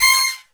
C6 POP FALL.wav